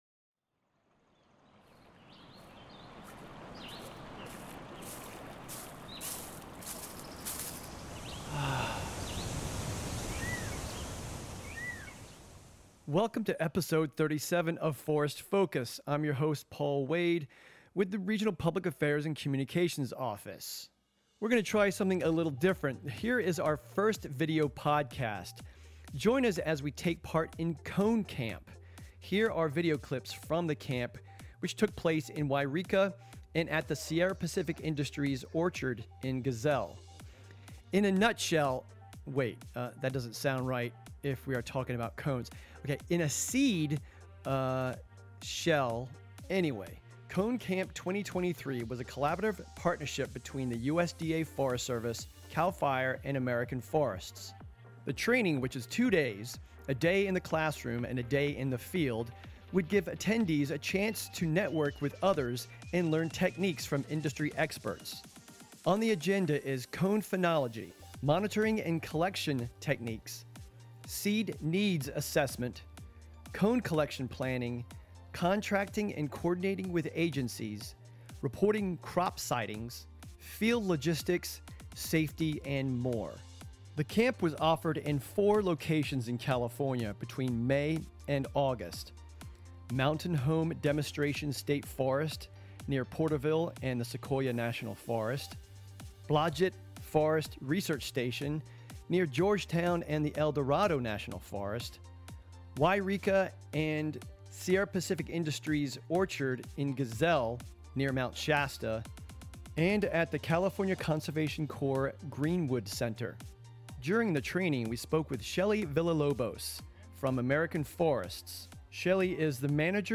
This is the audio version of our first Videocast from one of the four Cone Camps held in 2023. The camps are built around a day in the classroom and a day in the field.